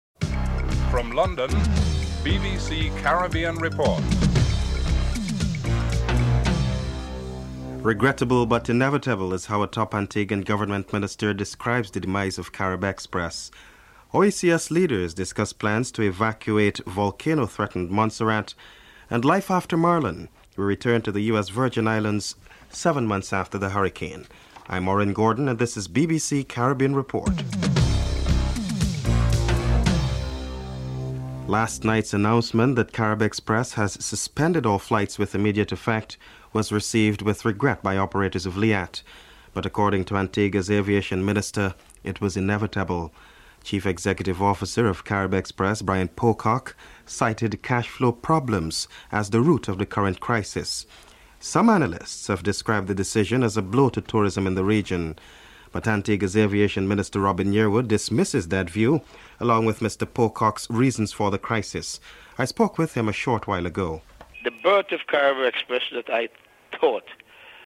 Antigua Aviation Minister Robin Yearwood and Prime Minister James Mitchell are interviewed (00:31-06:07)
3. OECS leaders discuss plans to evacuate volcano threatened Montserrat. Prime Minister James Mitchell is interviewed (06:08-08:26)